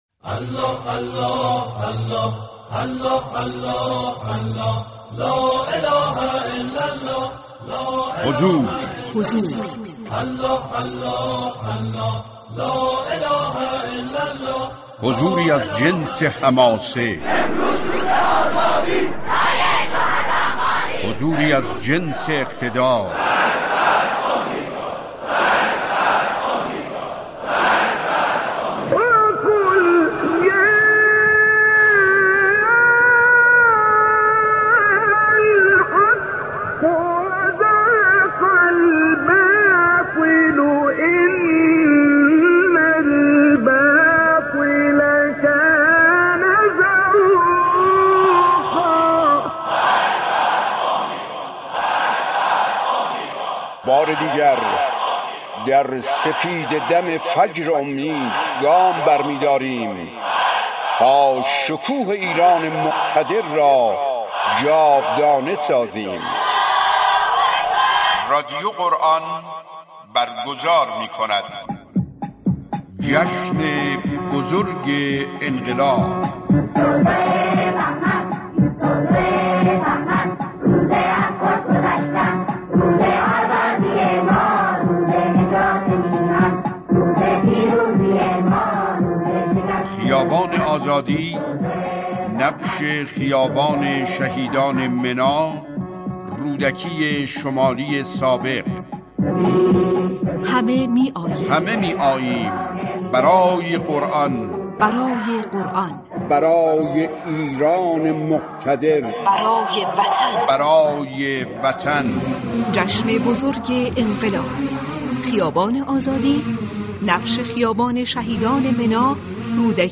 این ویژه برنامه‌ها شامل گفت‌وگوهای متنوع، گزارش‌های مردمی، پخش بیانات حضرت امام خمینی (ره) و مقام معظم رهبری (مدظله العالی) خواهد بود.